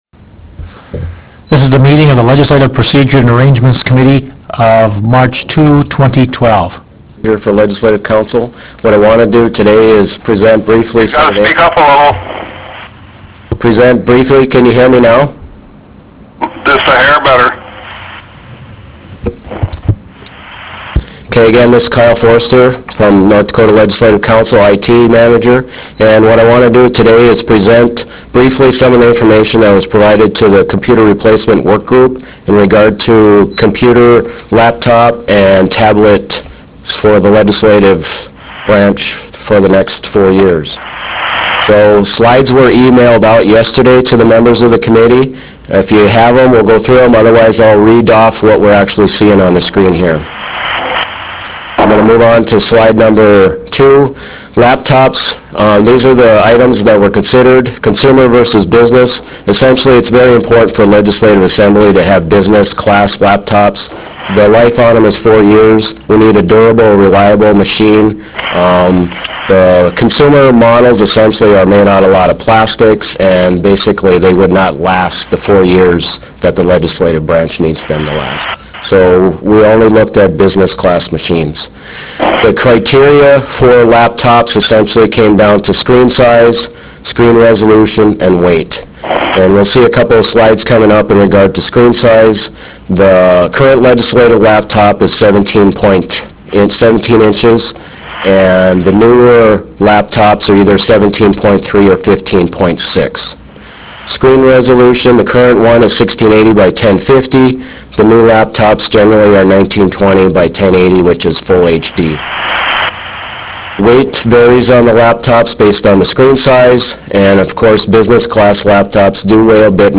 Harvest Room State Capitol Bismarck, ND United States